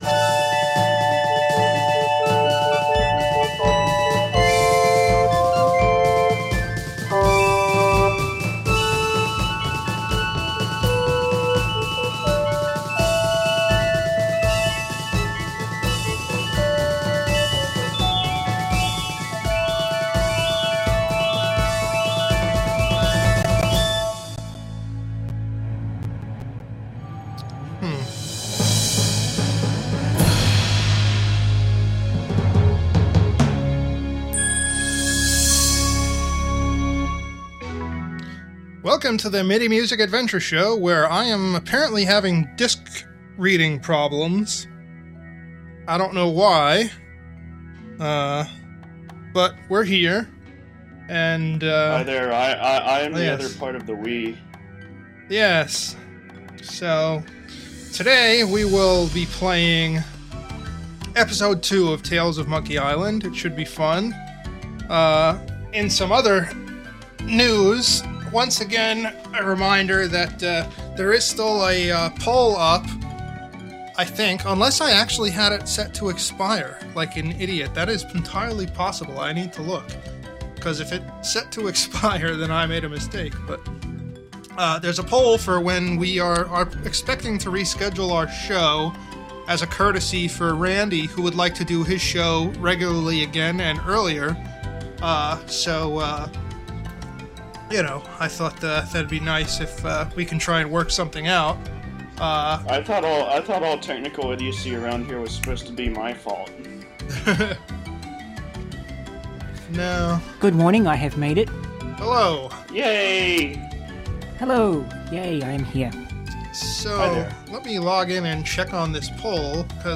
Also, we are joined by various cool people on TeamTalk.
EDIT: looks like the archive recording skips forward a few times, I don’t know why, sorry about that.